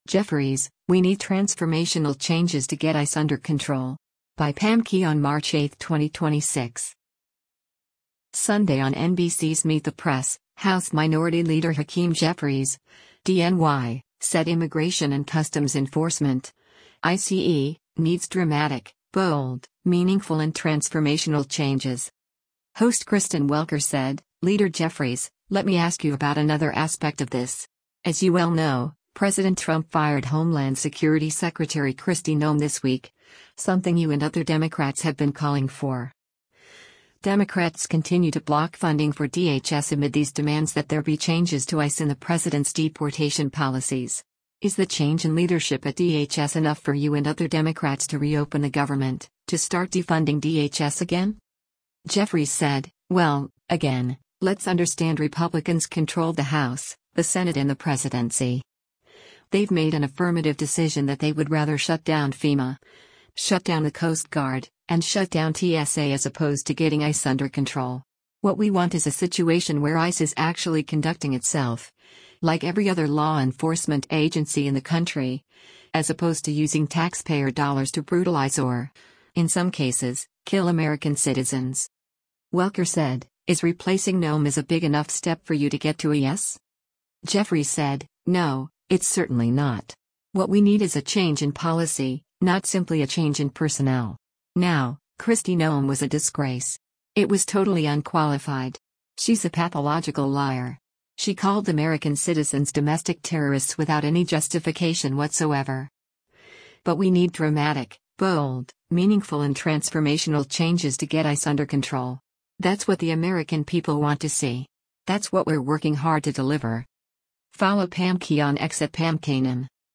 Sunday on NBC’s “Meet the Press,” House Minority Leader Hakeem Jeffries (D-NY) said Immigration and Customs Enforcement (ICE) needs “dramatic, bold, meaningful and transformational changes.”